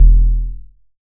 WICKED BASS.wav